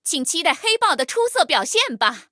黑豹编入语音.OGG